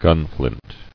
[gun·flint]